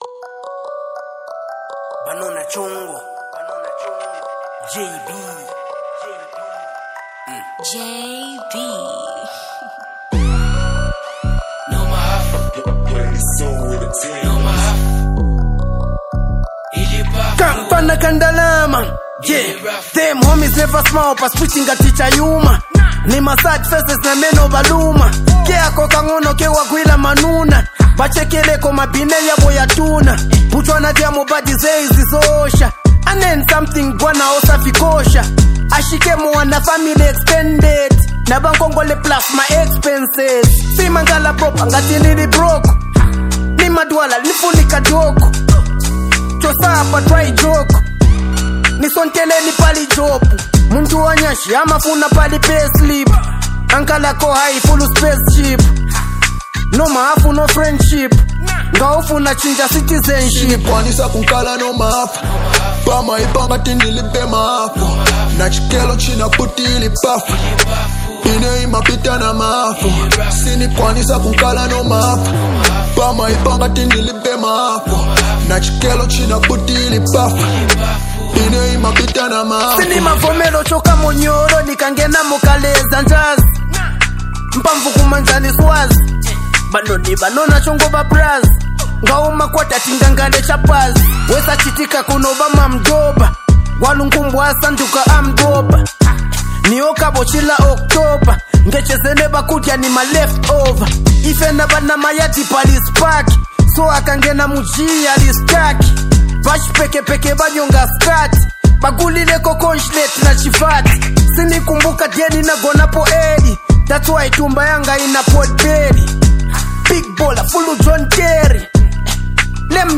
making it a perfect mix of old-school and new-school vibes.